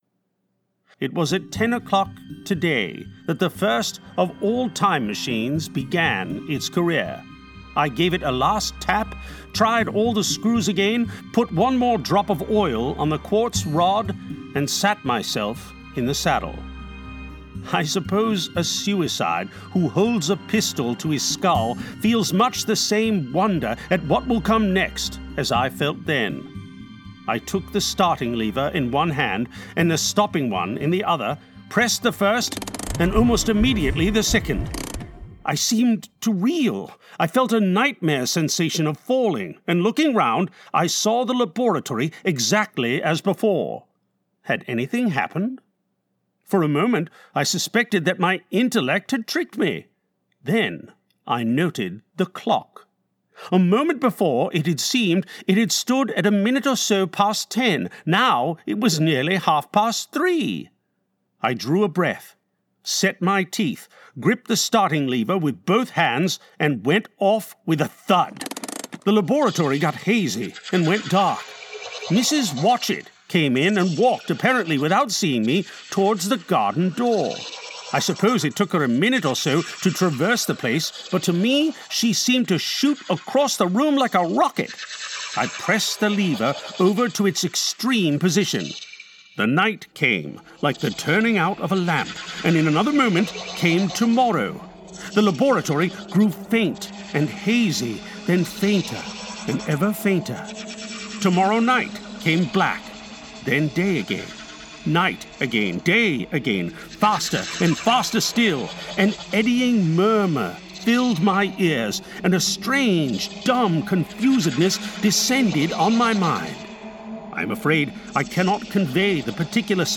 SoundCraft Audiobooks | The Time Machine